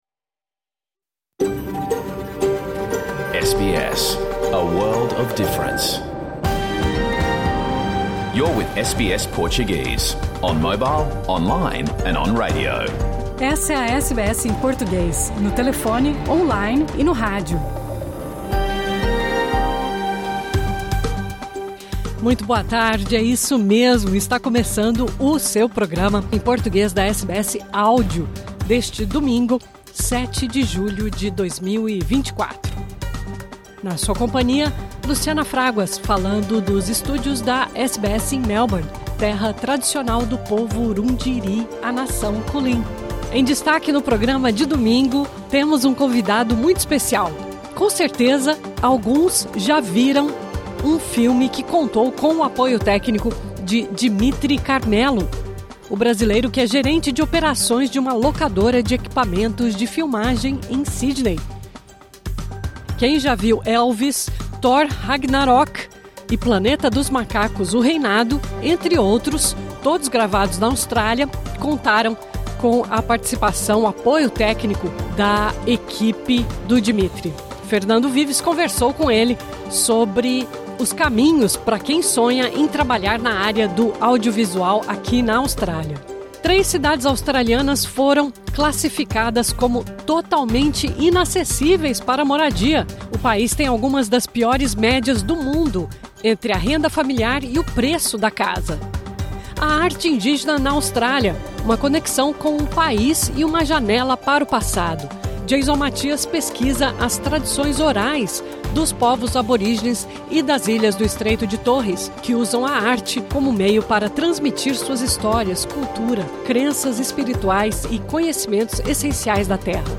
Programa completo que foi ao ar hoje na Austrália.